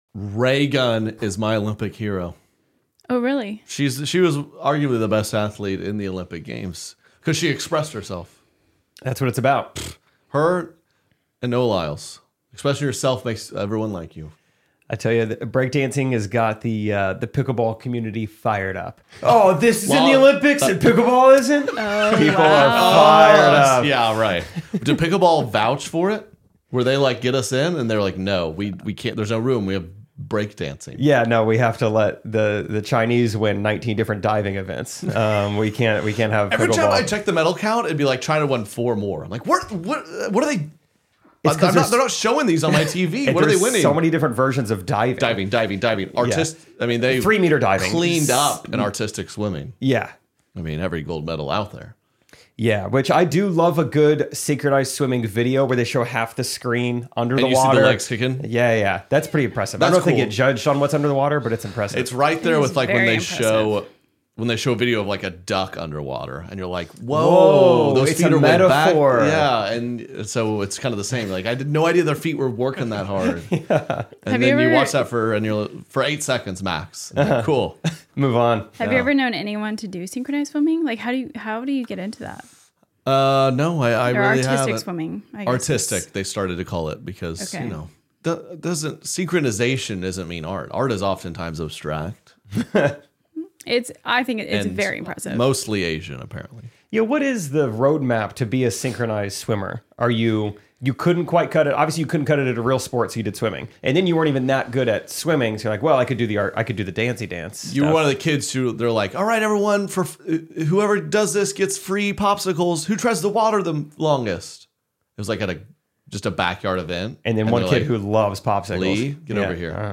Dusty Slay, a comedian with two Netflix specials, is the first guest in the new studio. He talks about his experiences performing in Kansas City and the appreciation for clean comedy in the area. Dusty talks about the joys and challenges of being a parent and how it has changed his perspective on certain things.